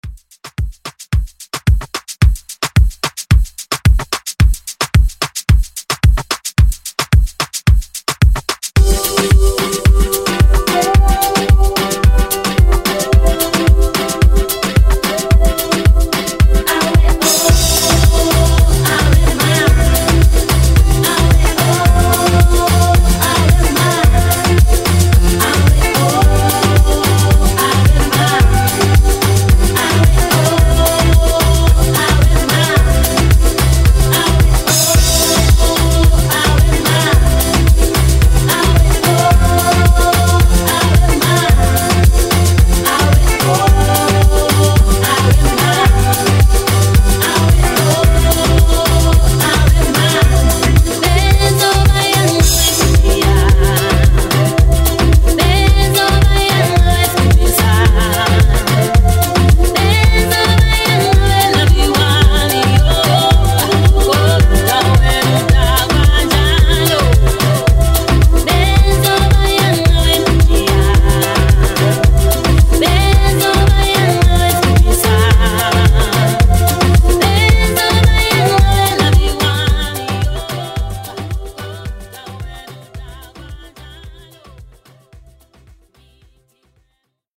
Genre: GERMAN MUSIC
Clean BPM: 120 Time